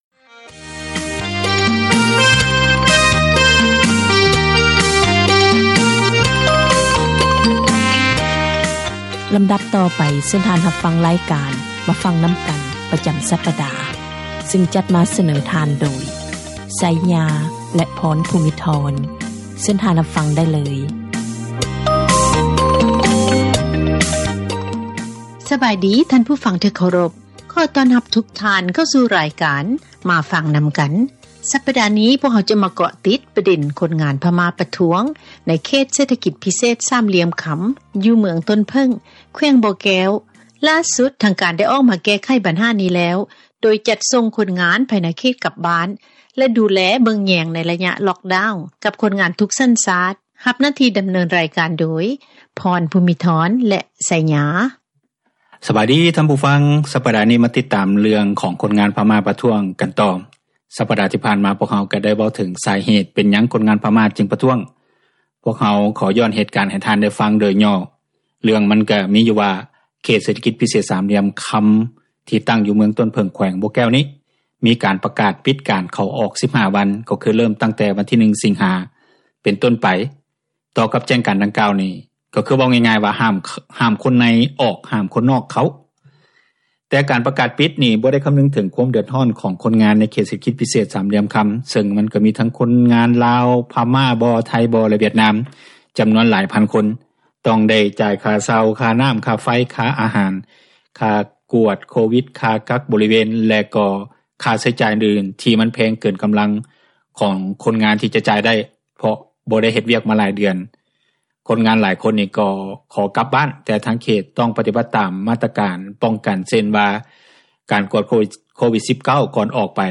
ການສົນທະນາ ໃນບັນຫາ ແລະ ຜົລກະທົບຕ່າງໆ ທີ່ເກີດຂຶ້ນ ຢູ່ປະເທດລາວ.